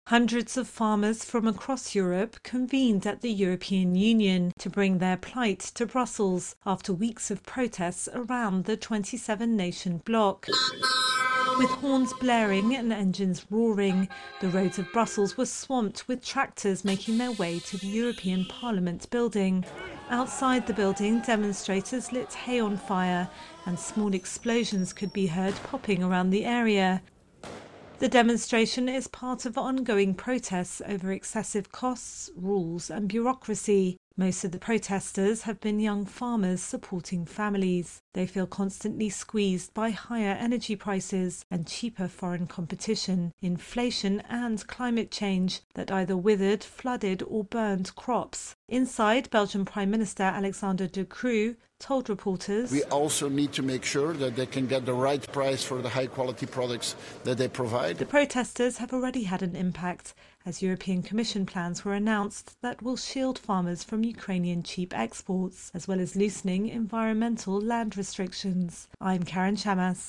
Horns blaring and engines roaring, hundreds of tractors bring farmers' plight to an EU summit